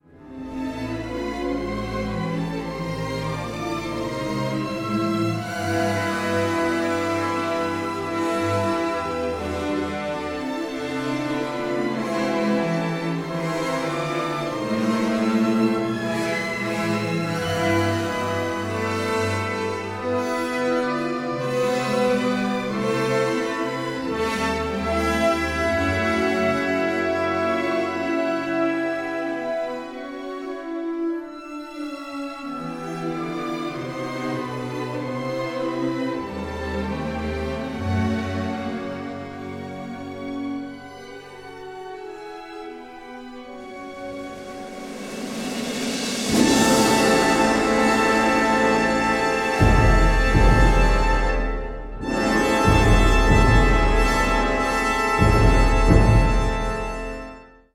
mezzo-soprano
Cantata for mezzo-soprano, mixed choir and orchestra